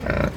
animalworld_boar.ogg